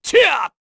Roman-Vox_Attack3_kr.wav